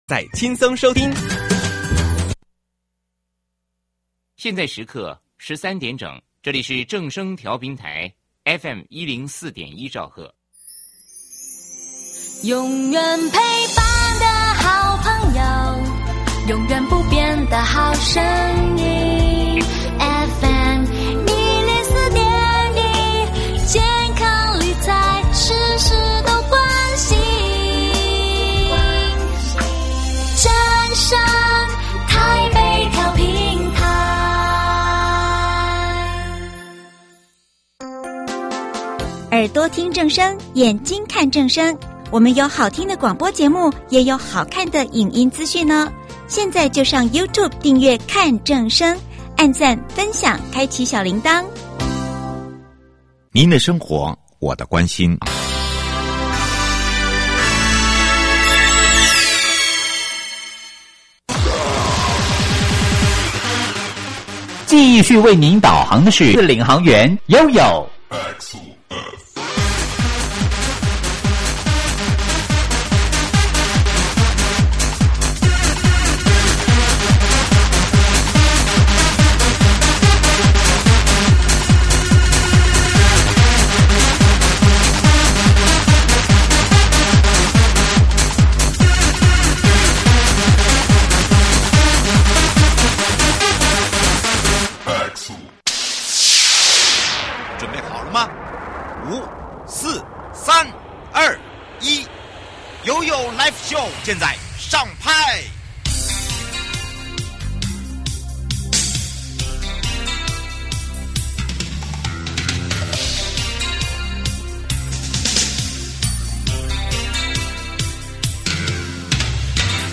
受訪者： 營建你我他 快樂平安行~七嘴八舌講清楚~樂活街道自在同行!(六) 主題：宜蘭勁好行！